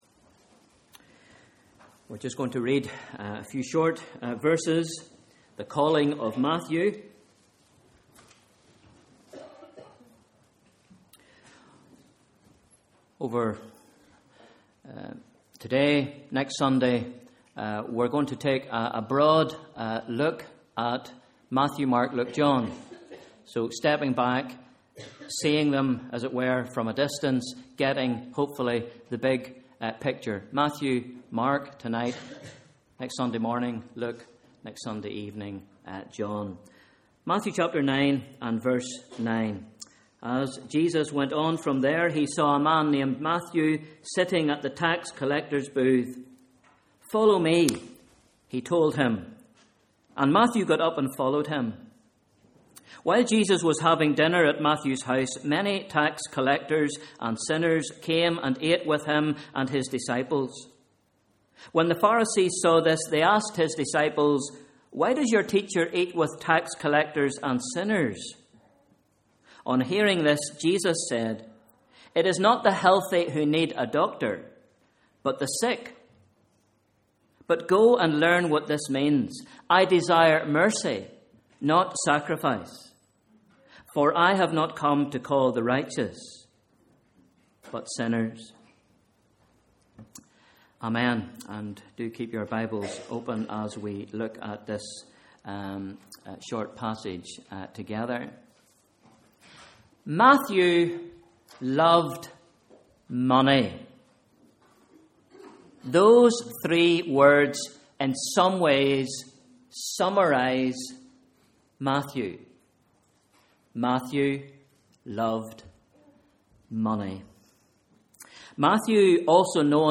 Morning Service: Sunday 17th November 2013 Bible Reading: Matthew 9 v 9-19